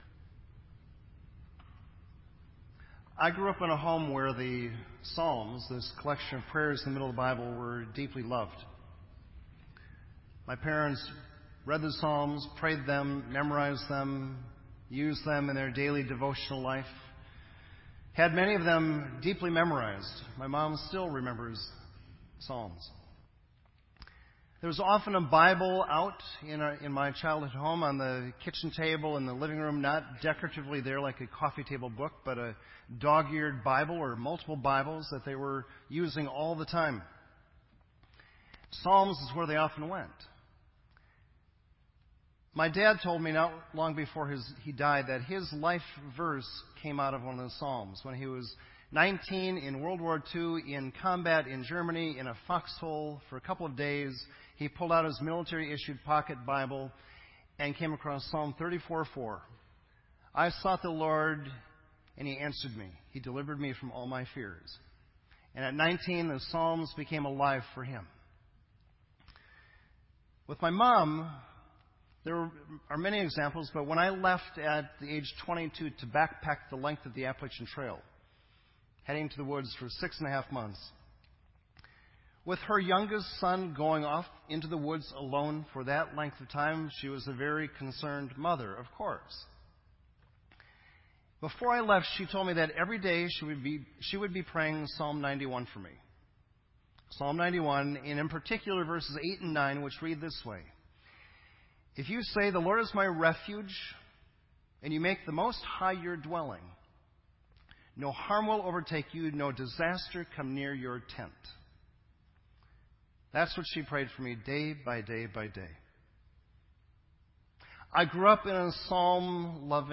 This entry was posted in Sermon Audio on August 10